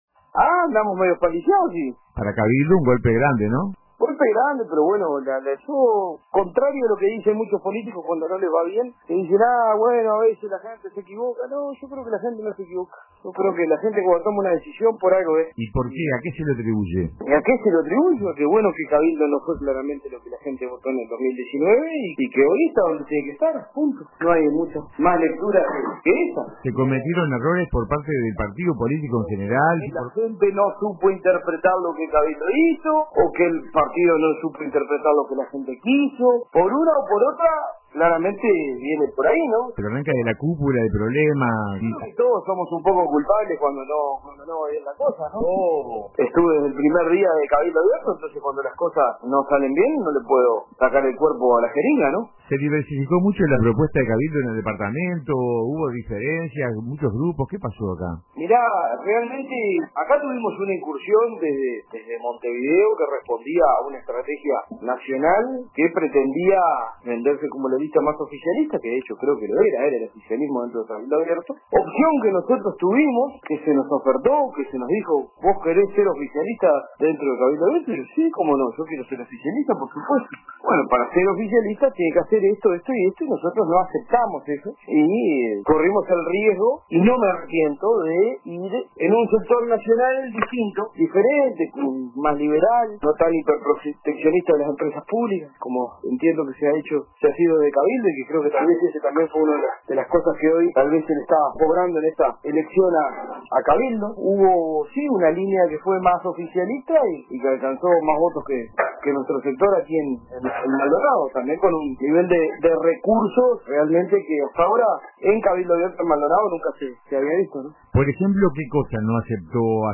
En declaraciones al programa RADIO CON TODOS de RBC, Cal reflexionó sobre los resultados adversos para Cabildo Abierto, reconociendo que el partido no logró captar el apoyo de la ciudadanía como en 2019.